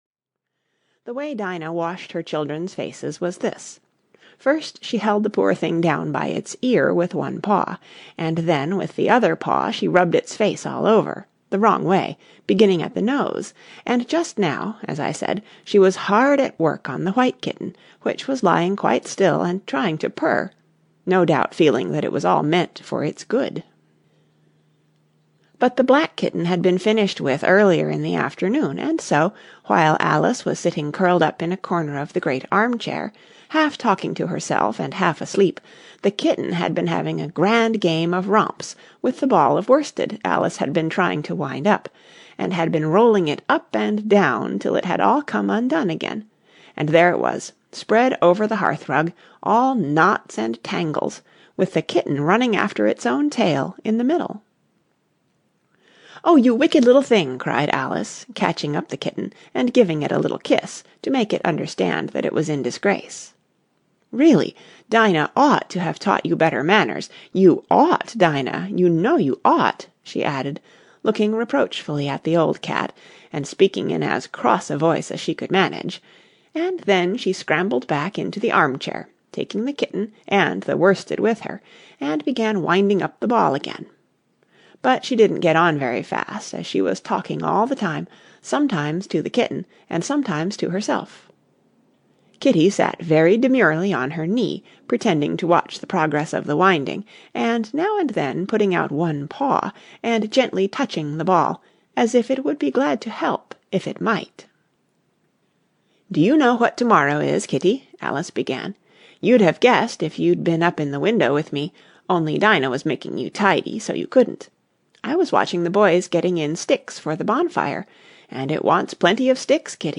Through the Looking-glass and What Alice Found There (EN) audiokniha
Ukázka z knihy